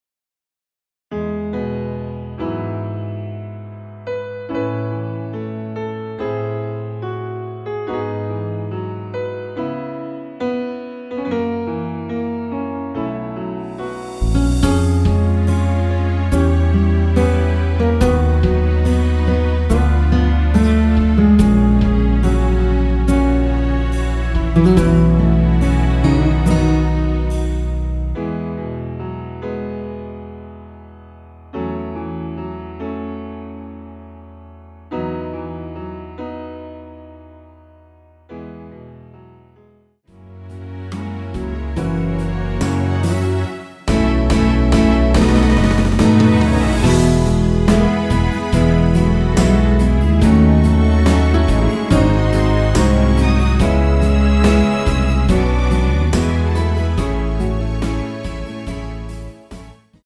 MR 입니다.
키 E 가수